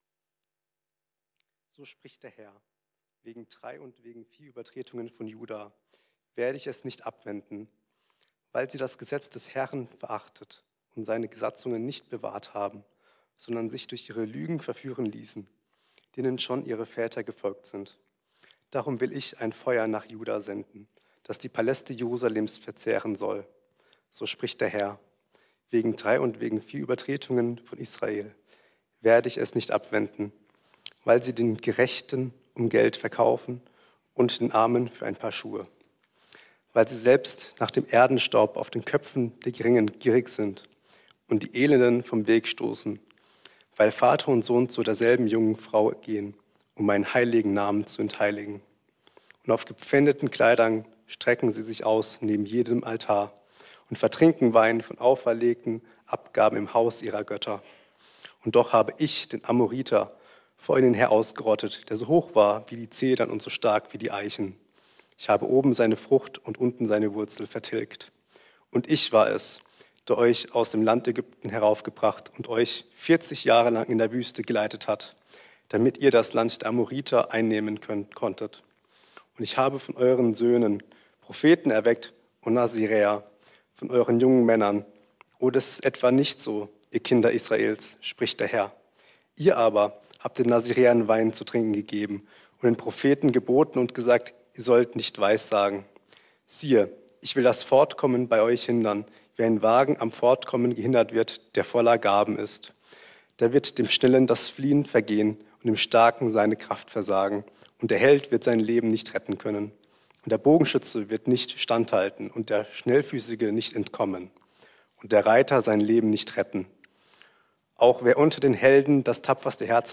Wahrheit Glauben, Glauben Leben ~ Mittwochsgottesdienst Podcast